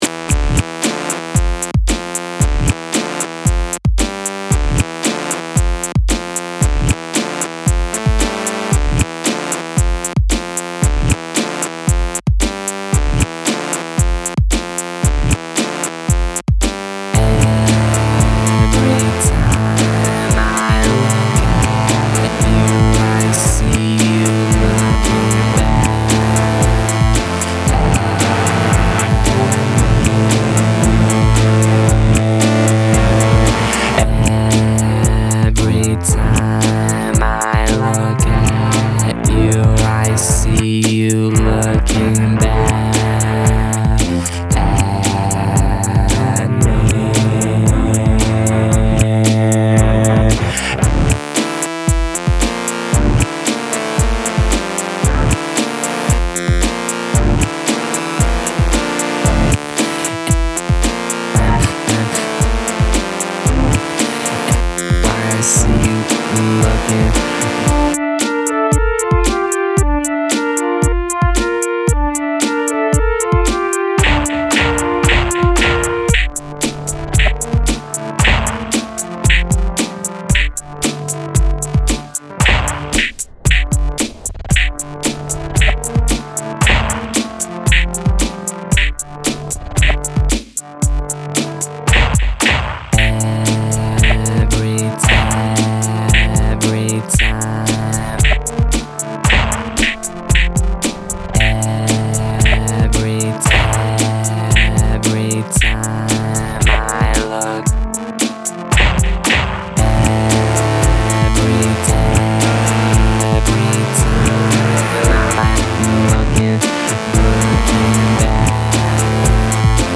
electro/synth territory